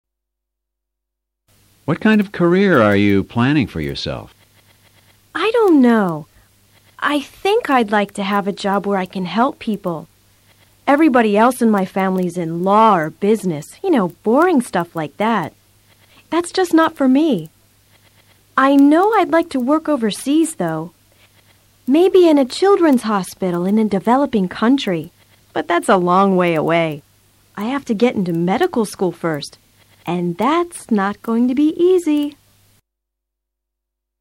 A continuación escucharás a tres entrevistadores.